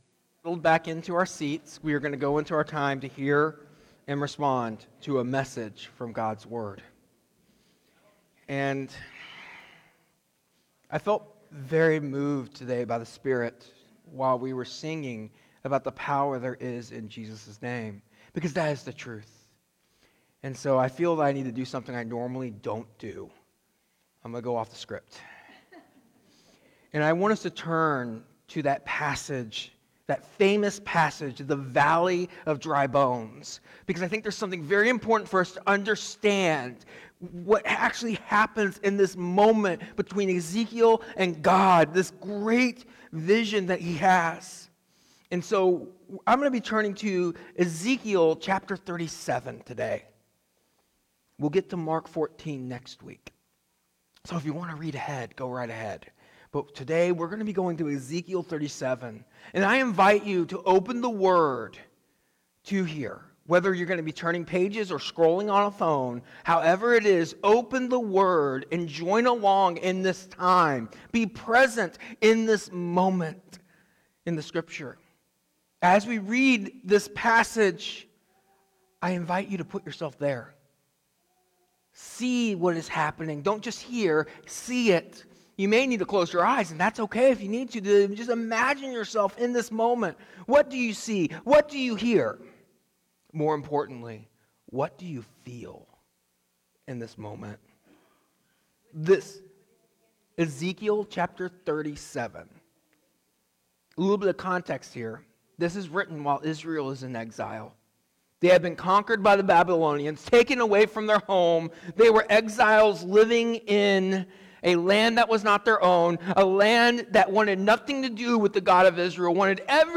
Today’s message was a bit off the cuff; the Spirit of God led me to preach on Ezekiel 37 to help us all, including myself, understand our present circumstances do not dictate our future. There is always hope in Jesus, because there is might power in His name and in the work of His Holy Spirit.